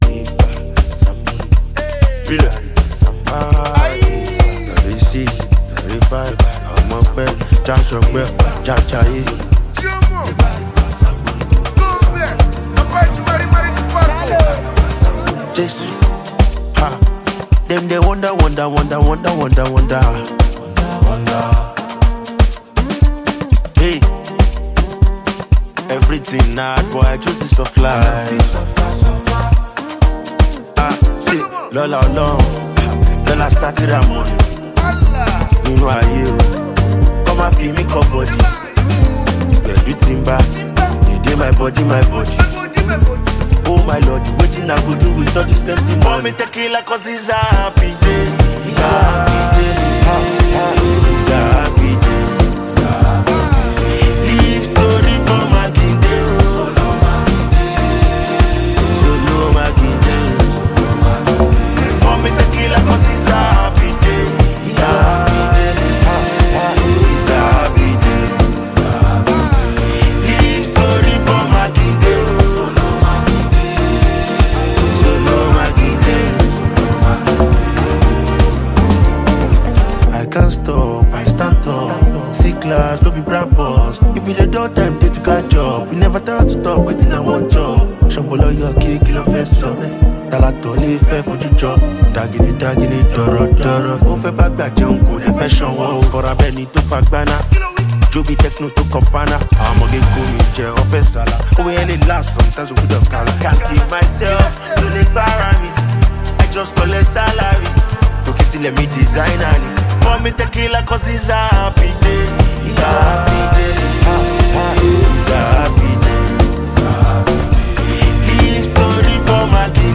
street-hop
a more reflective yet upbeat turn
catchy afrobeats rhythms with motivational lyrics